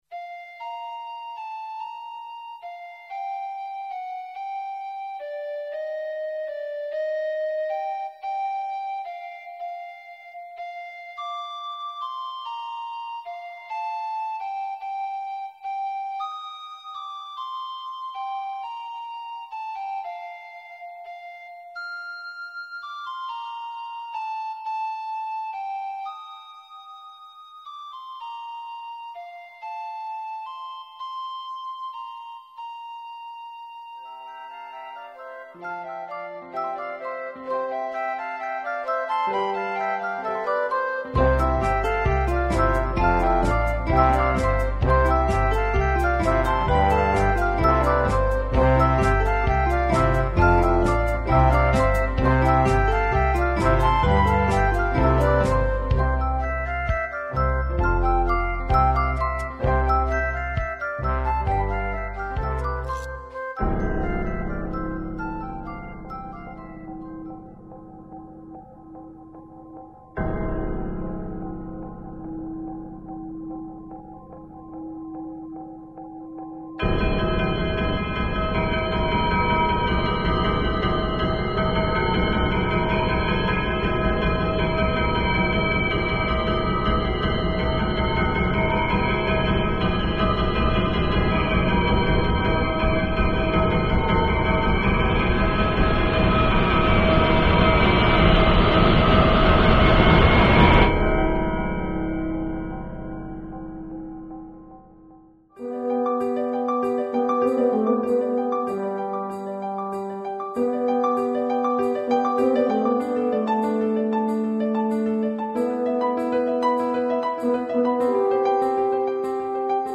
Orchestral & Instrumental Composer
A theatrical rendition of the famous Dickens story, demanding wistful melodies painted inbetween the darker undertones of Marley's ghost and the spirits.